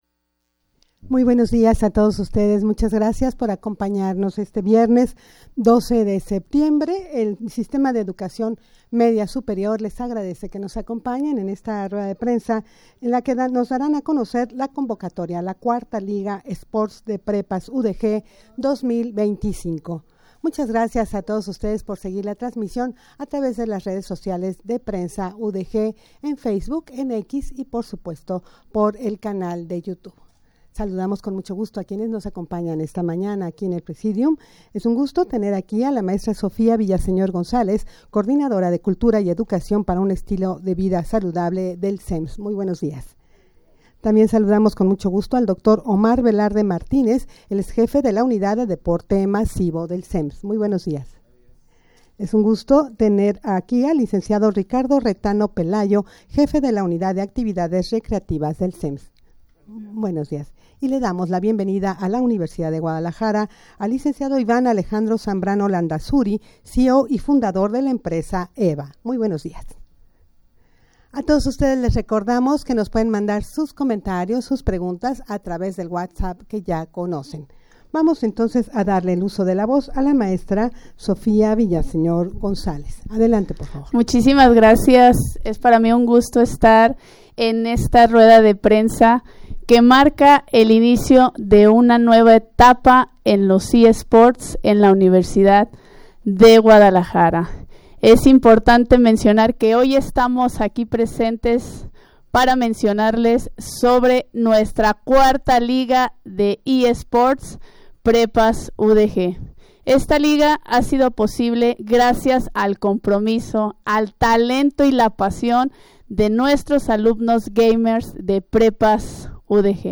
Audio de la Rueda de Prensa
rueda-de-prensa-para-dar-a-conocer-convocatoria-4ta.-liga-esports-de-prepas-udg-2025.mp3